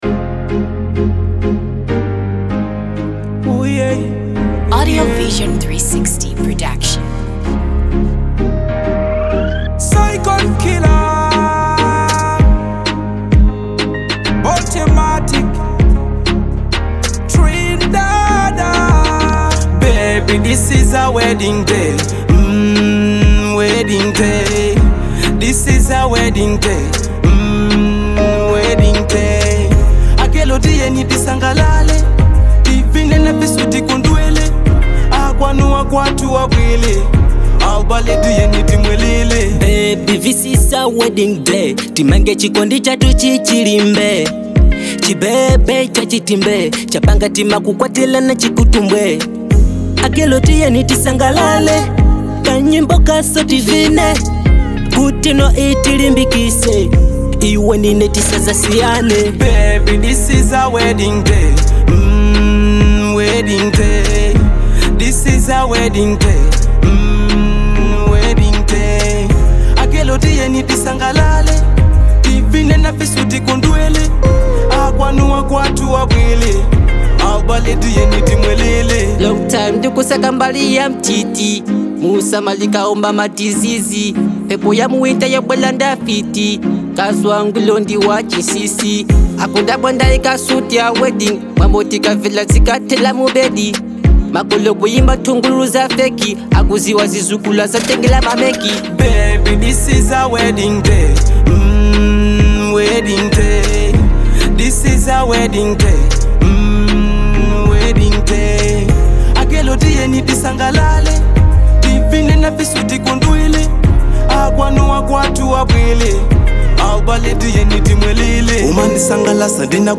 Ku Malawi kuli Dancehall yokoma